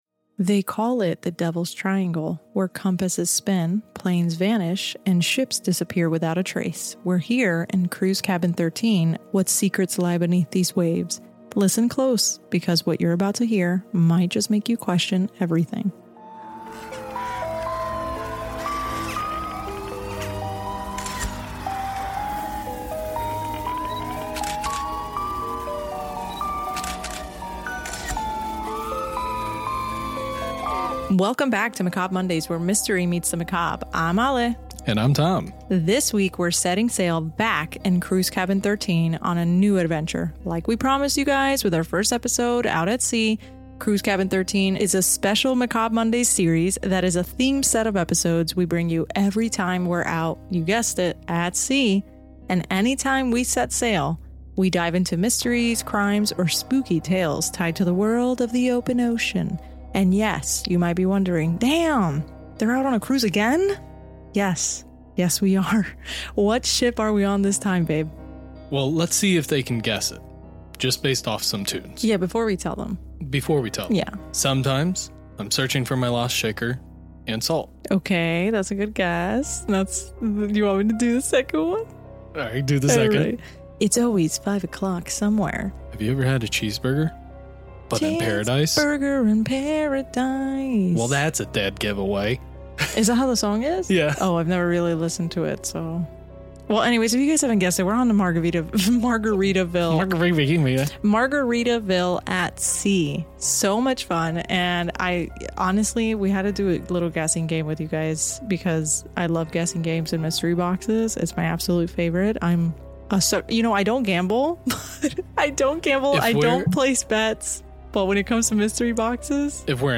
Welcome back to Cruise Cabin 13, our special series reserved for ocean-bound episodes recorded while we’re cruising the high seas.